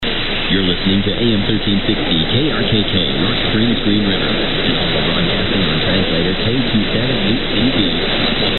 (recording) - Station received on the RSP2 Pro SDR (Software Defined Radio)